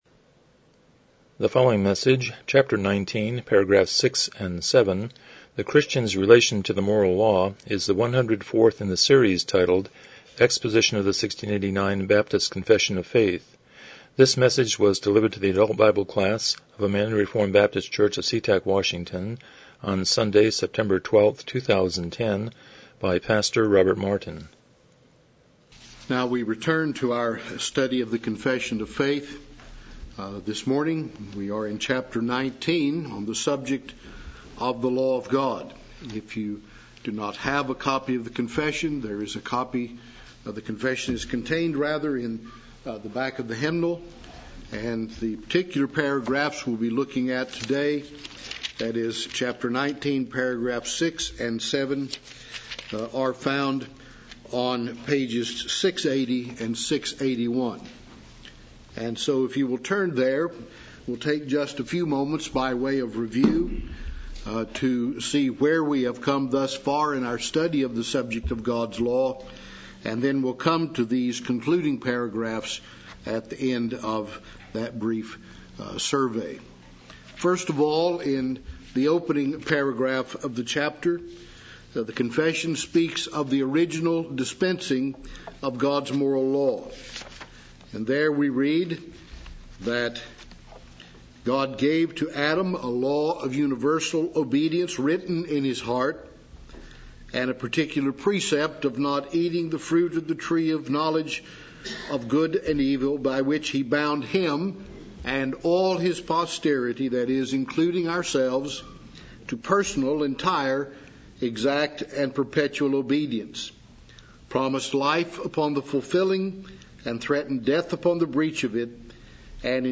1689 Confession of Faith Service Type: Sunday School « Practical Atheism